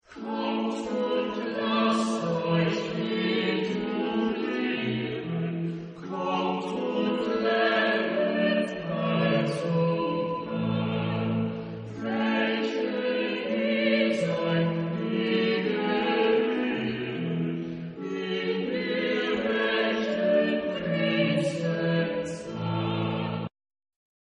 Genre-Style-Forme : Sacré
Type de choeur : SATB  (4 voix mixtes )